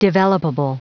Prononciation du mot developable en anglais (fichier audio)
Prononciation du mot : developable